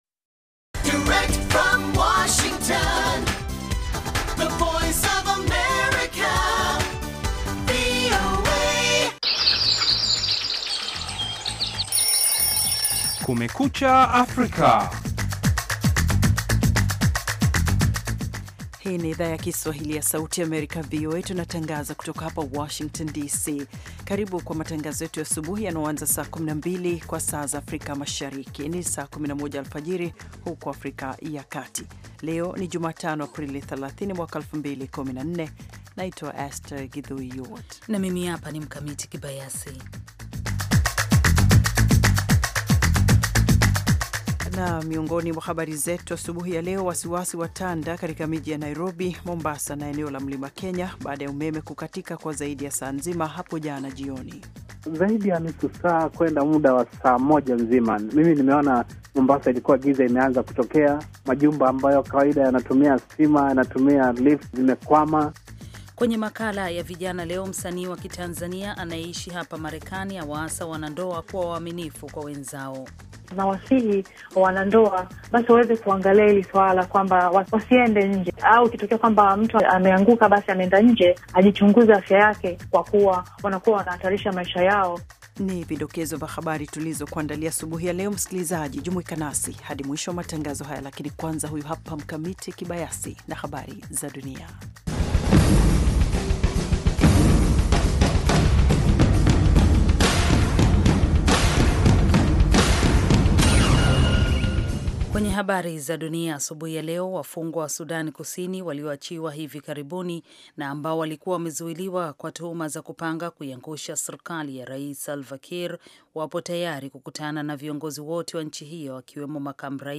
Matangazo ya nusu saa kuhusu habari za mapema asubuhi pamoja na habari za michezo.